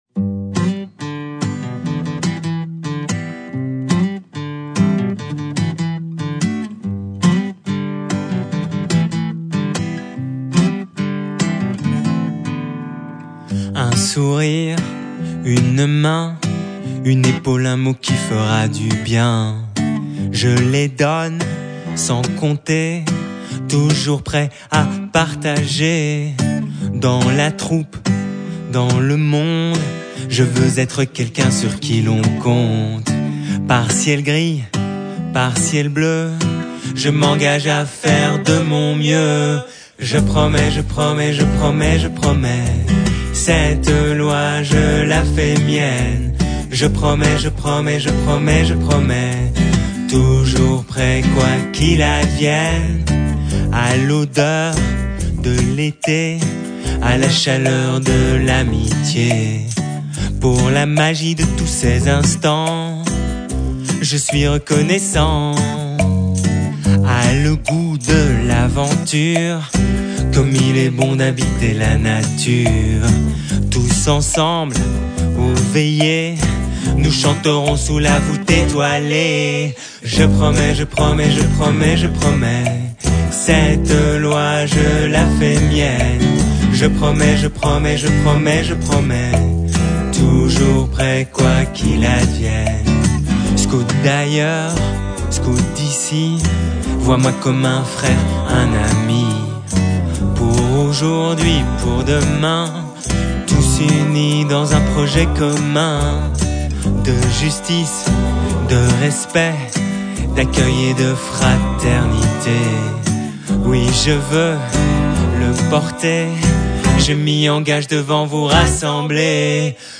Chanson disponible en version courte, version complète, version guitare ou version instrumentale.